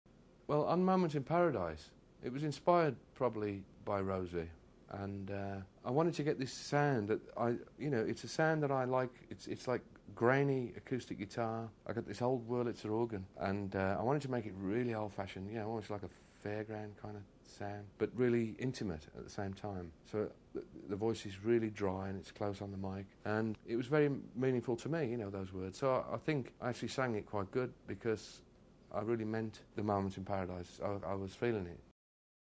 ELO Interview Disc